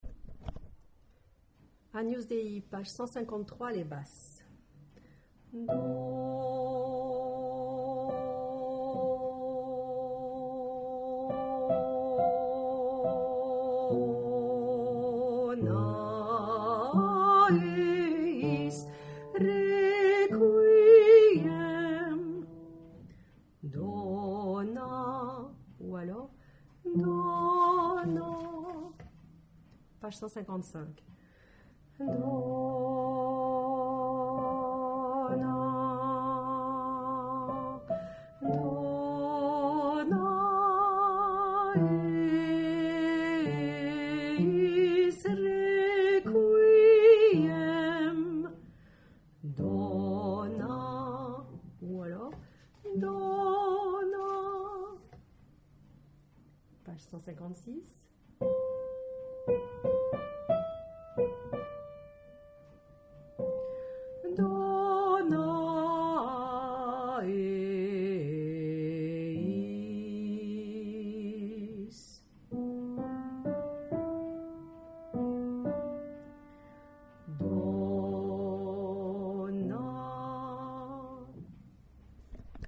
Basse p 153
agnusdeip153_Basse.mp3